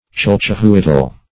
Search Result for " chalchihuitl" : The Collaborative International Dictionary of English v.0.48: Chalchihuitl \Chal`chi*huitl"\ (ch[.a]l`ch[-e]*w[-e]tl"), n. (Min.) The Mexican name for turquoise.